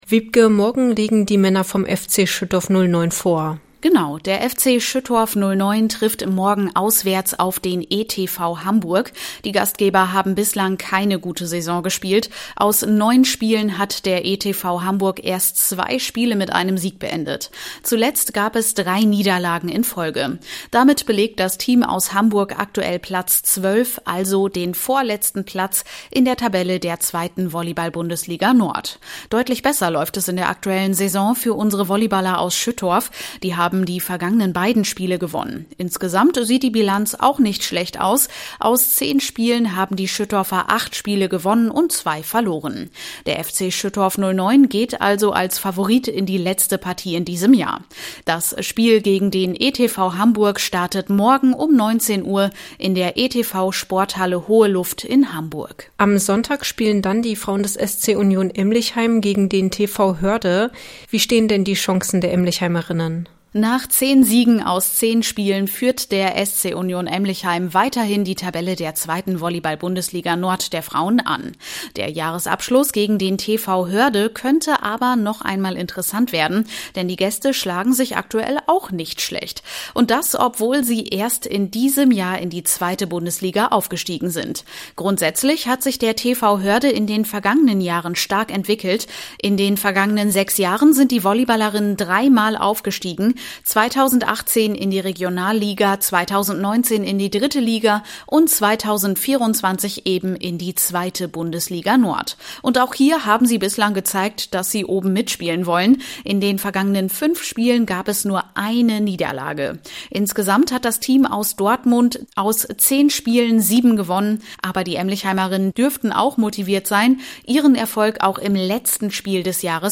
Sportvorschau: Jahresabschluss für Grafschafter Volleyballteams